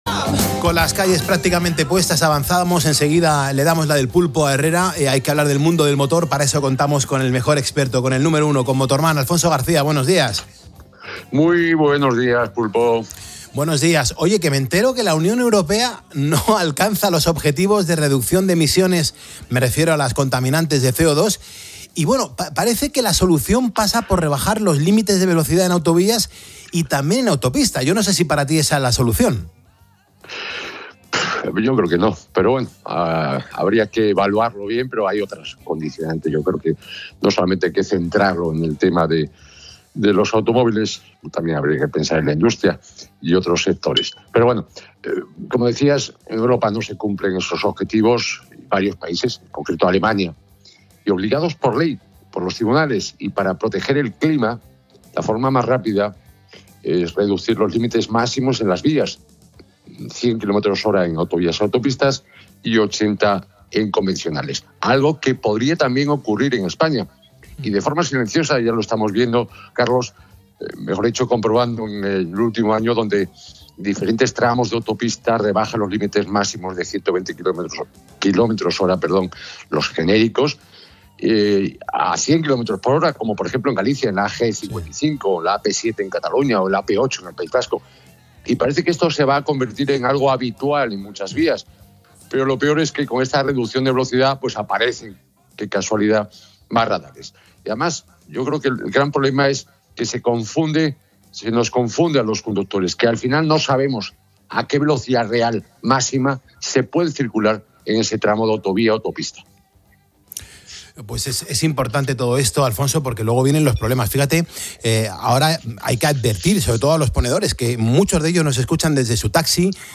ha charlado con el experto en motor